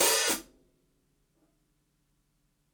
ROOMY_OPEN HH_2.wav